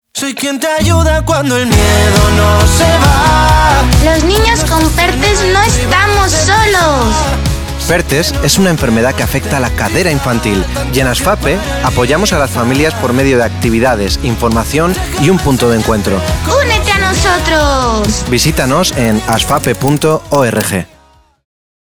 durante el próximo mes se emitirá una cuña publicitaria de ASFAPE en DIAL MADRID y LOS40 MADRID